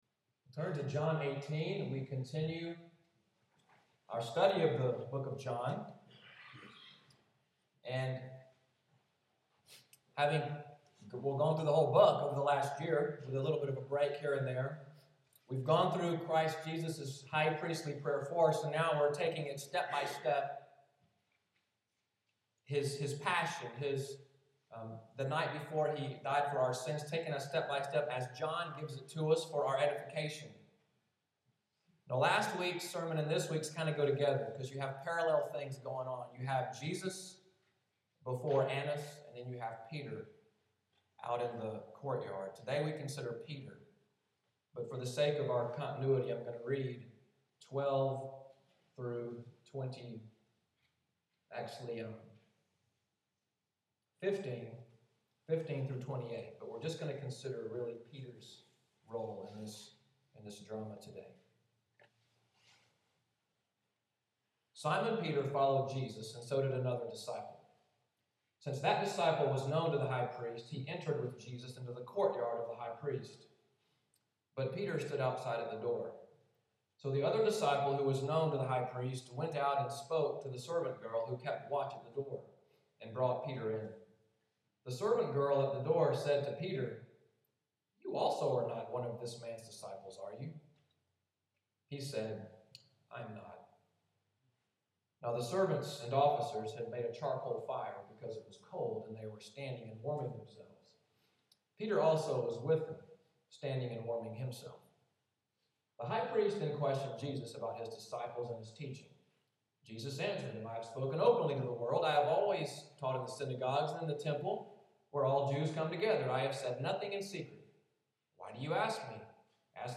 Audio from the sermon, “I Am Not,” preached March 2, 2014